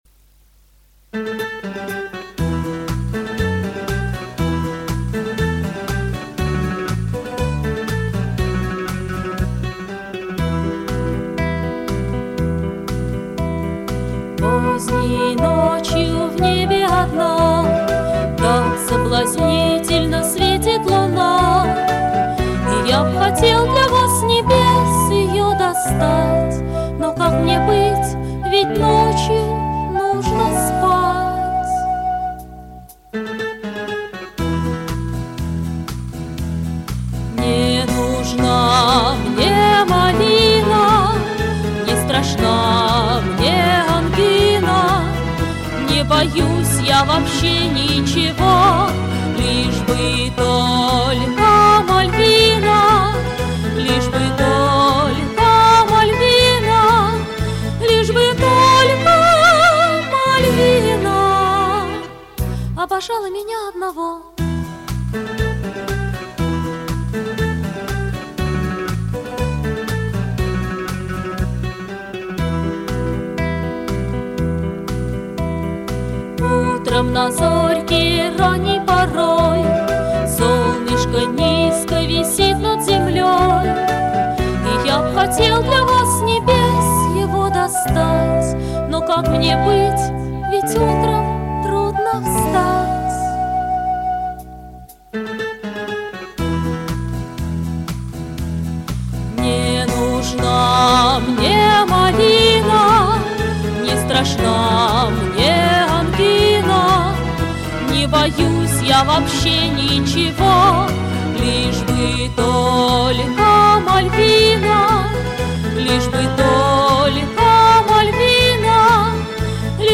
Тебе удалось спеть по-мальчишечьи сию песнь)))